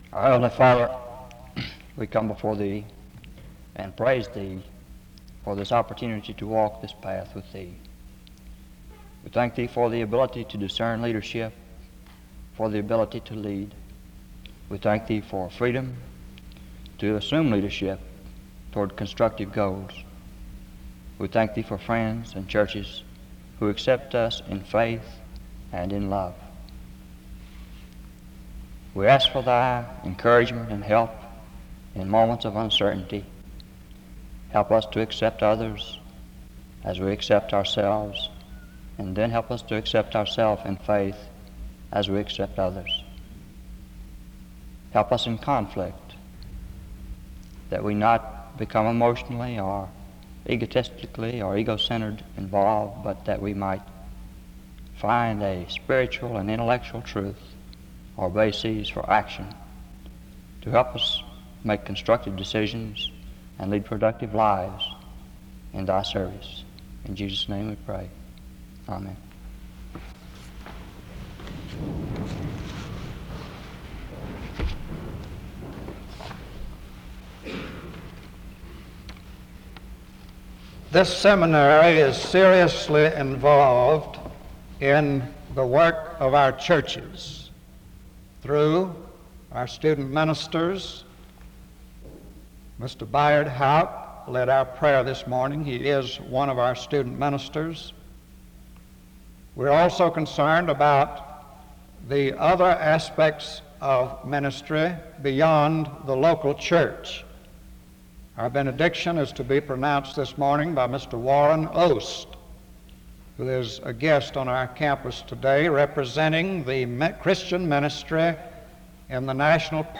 The service opens in a word of prayer from 0:00-1:13.
He preaches on the importance of a minister as well as the qualifications and the duties that the preacher must fulfill. A closing prayer is offered from 22:17-22:37.